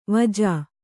♪ vajā